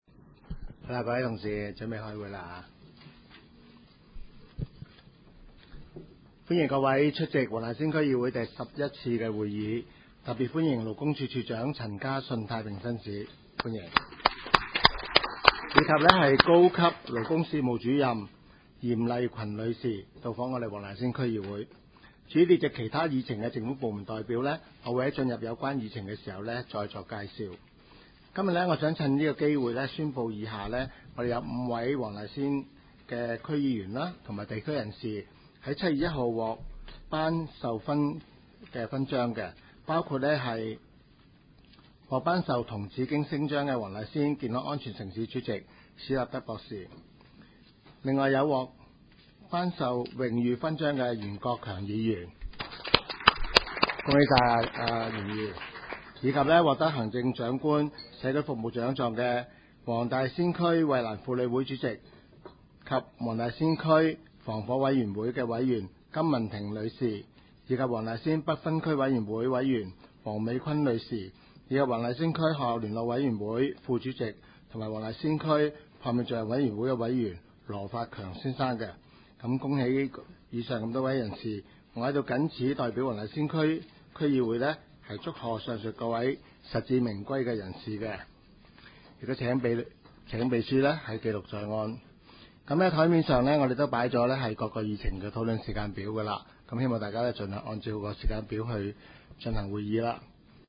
区议会大会的录音记录
黄大仙区议会会议室
主席致辞